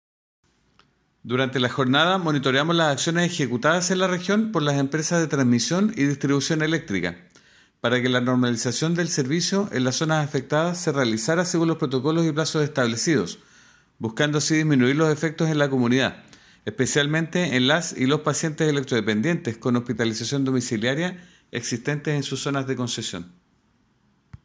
Audio: Manuel Cartagena, Director Regional SEC Biobío